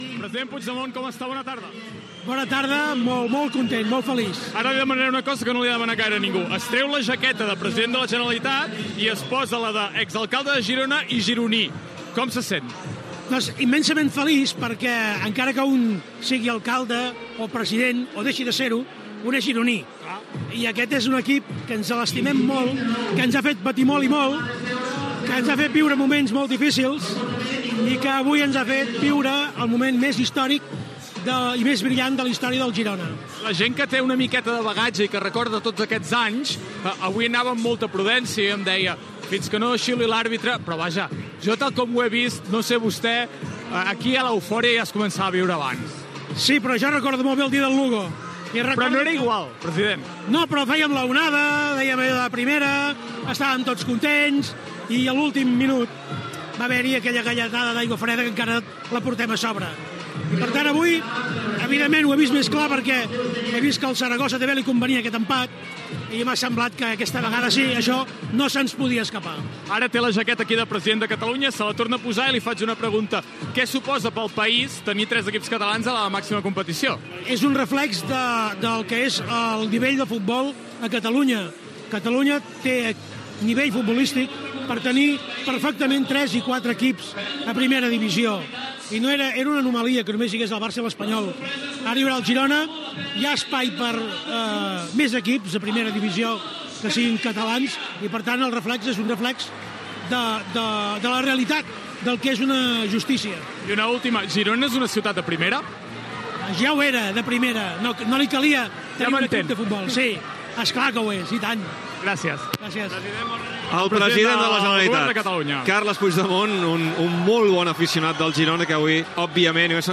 Declaracions del president de la Generalitat Carles Puigdemont en acabar empatat el partit de futbol Saragossa Girona.
Esportiu
FM